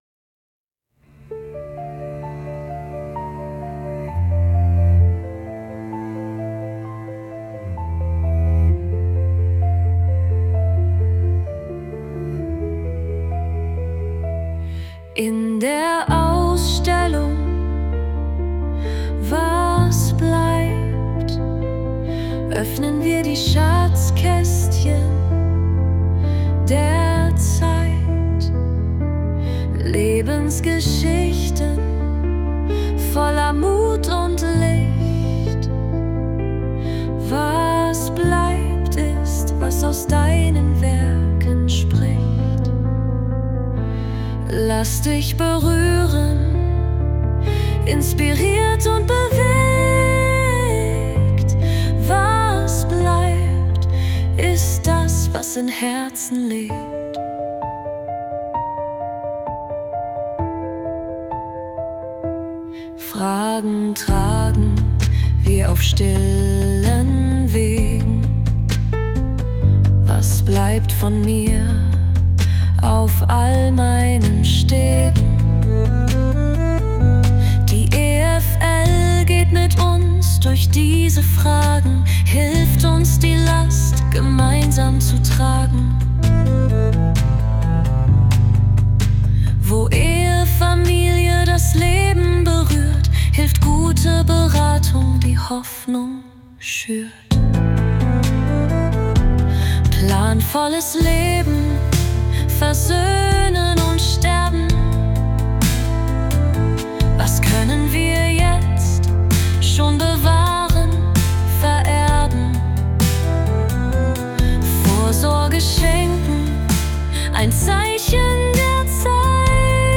Was-bleibt-der-Soundtrack-Klavier-und-Cello.mp3